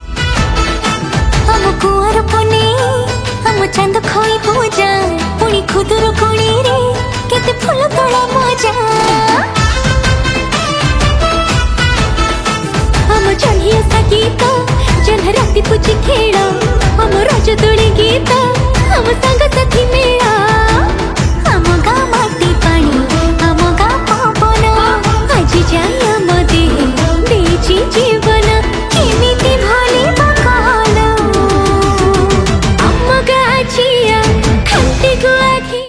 Odia Album Ringtones
dance song